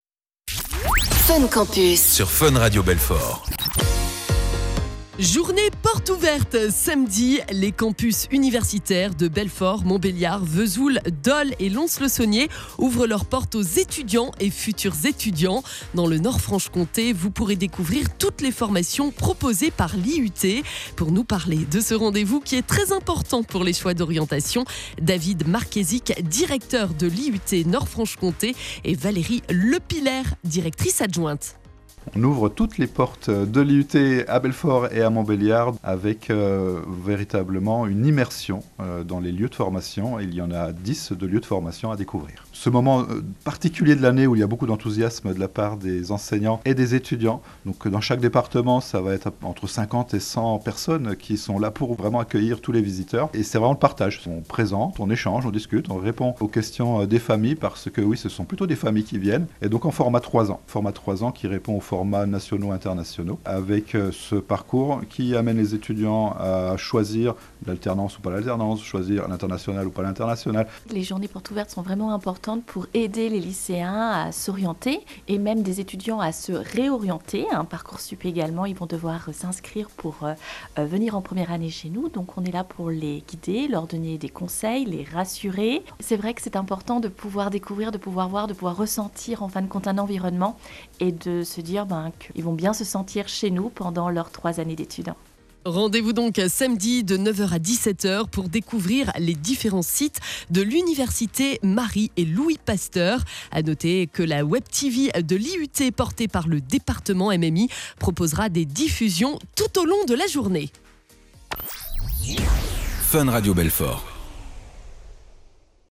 Interview JPO 2025 FUN RADIO